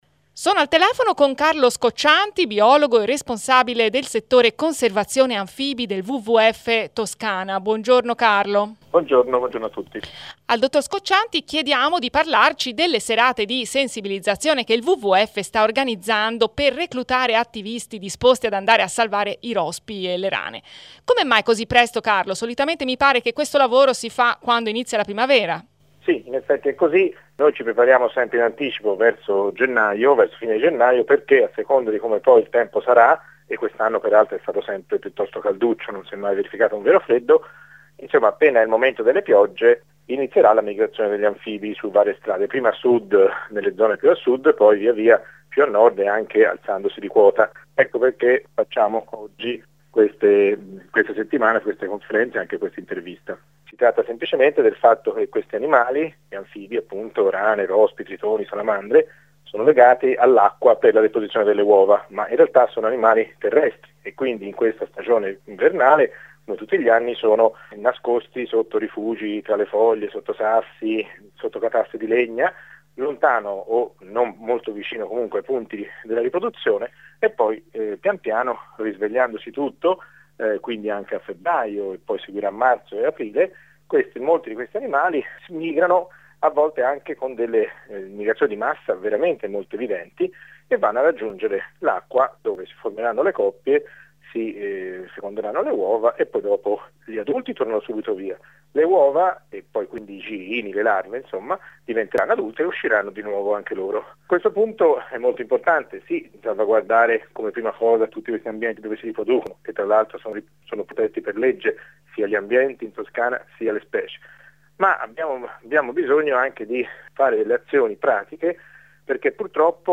Ne abbiamo parlato con